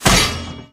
用锚攻击音效